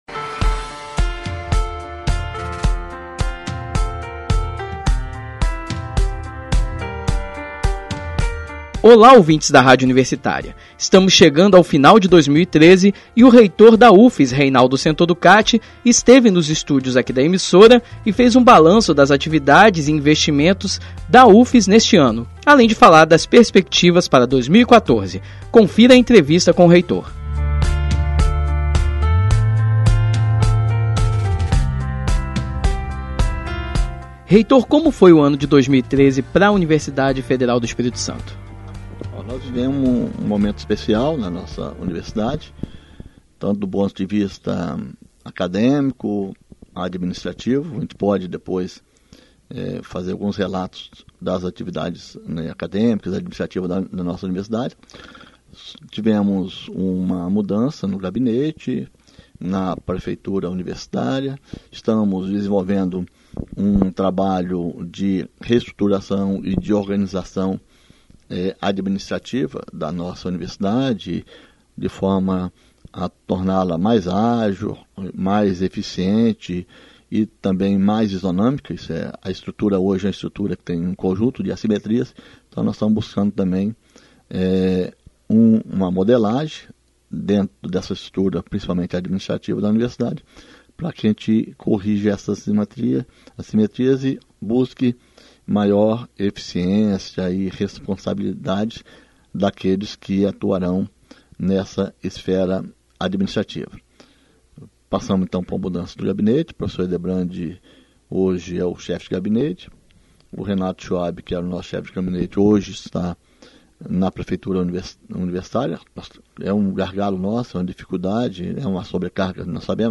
O reitor da Ufes, Reinaldo Centoducatte, esteve na Rádio Universitária e concedeu entrevista fazendo um balanço das atividades e investimentos da Ufes em 2013 e falou do planejamento para a Universitária em 2014.